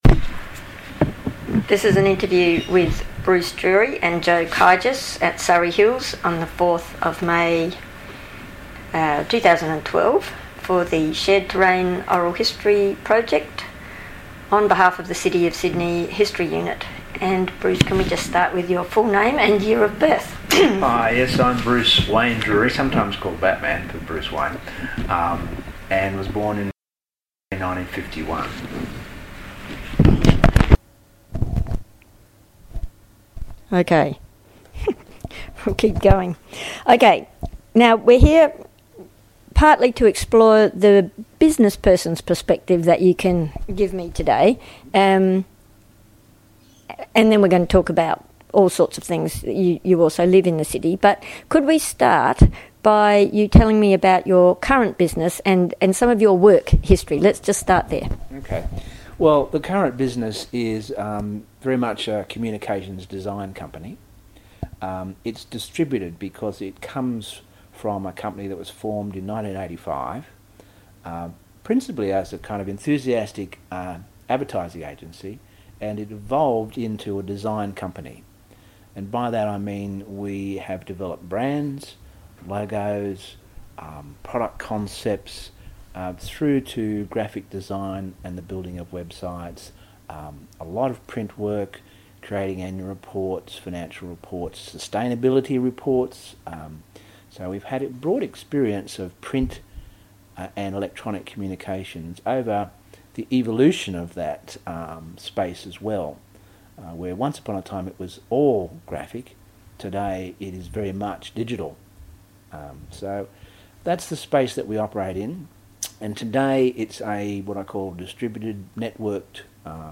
This interview is part of the City of Sydney's oral history theme: Shared Terrain